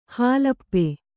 ♪ hālappe